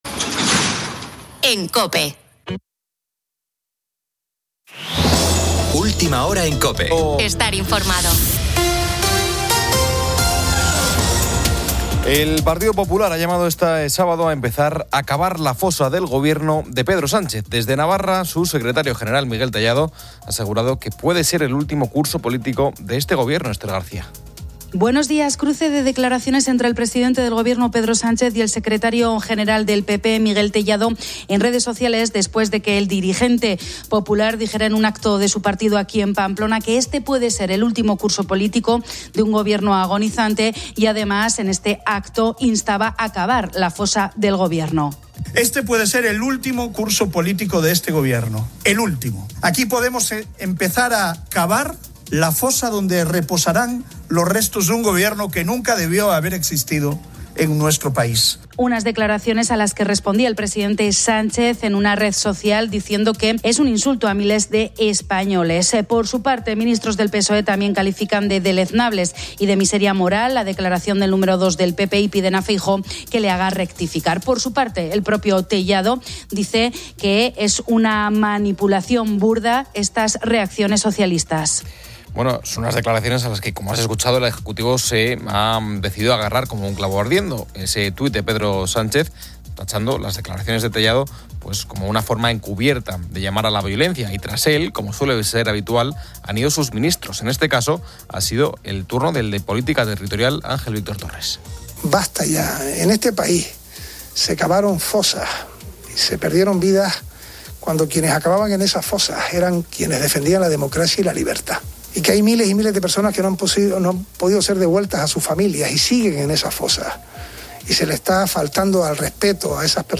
Finalmente, el programa de radio celebra su 11º aniversario con una entrevista